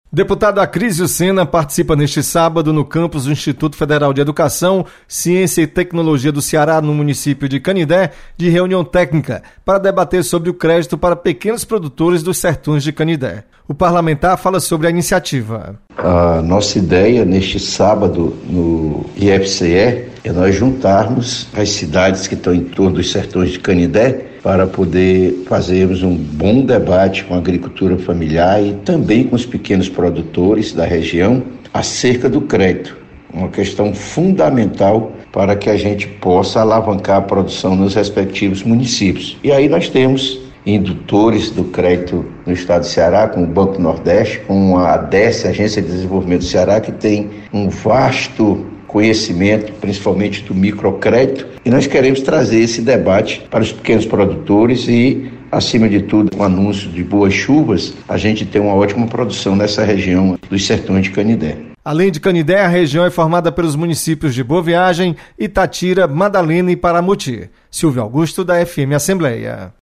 Acrísio Sena participa de debate no IFCE de Canindé sobre crédito para pequenos produtores.